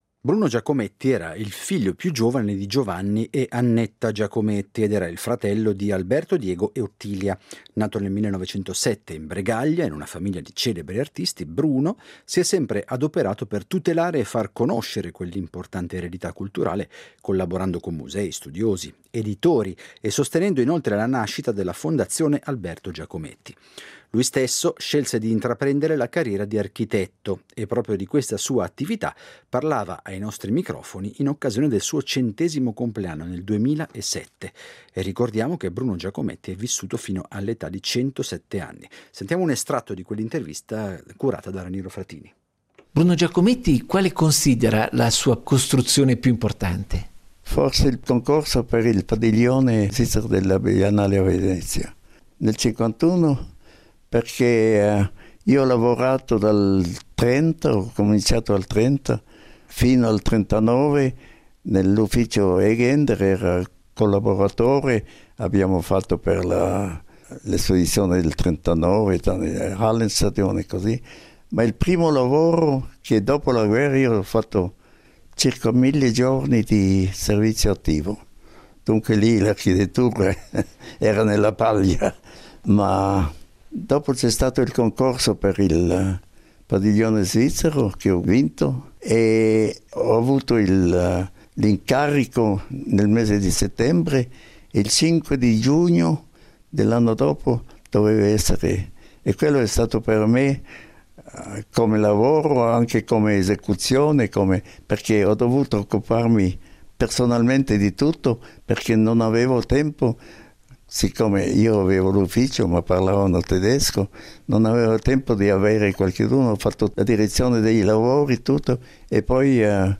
Diderot vi propone una serie di interviste che provengono dai nostri archivi.